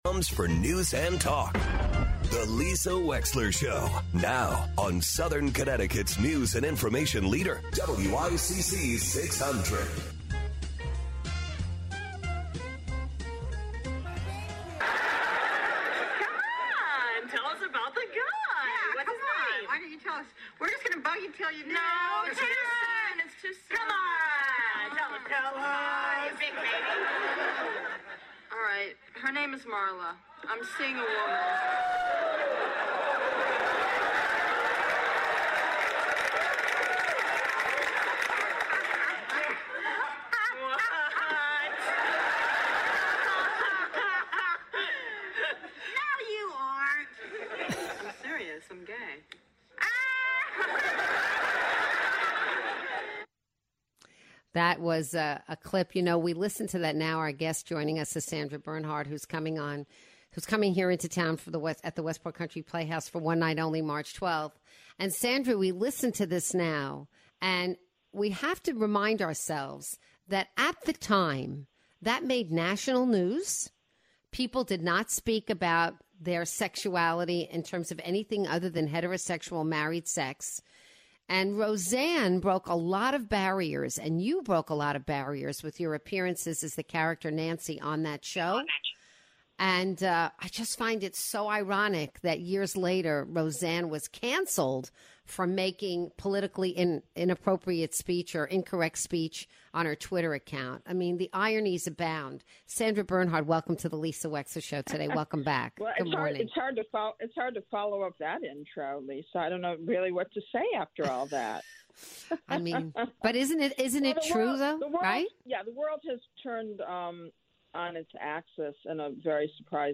Actress Sandra Bernhard joins the show to preview her upcoming show at the Westport Playhouse this weekend.